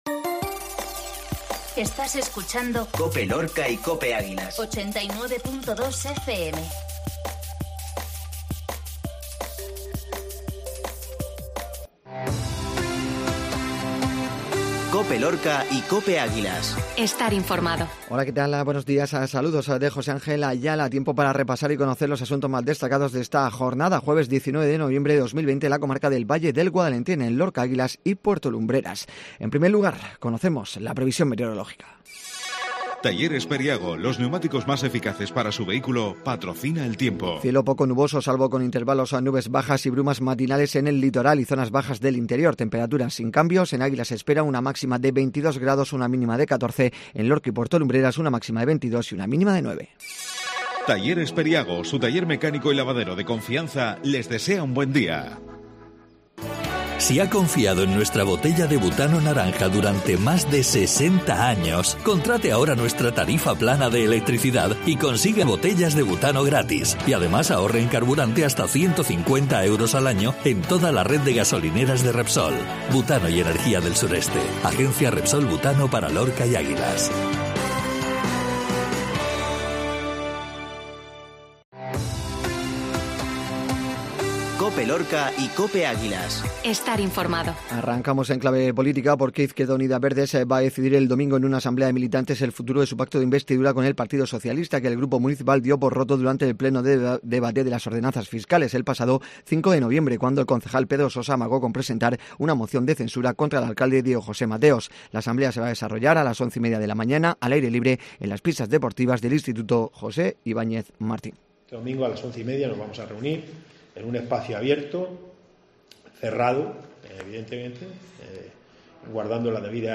INFORMATIVO MATINAL COPE JUEVES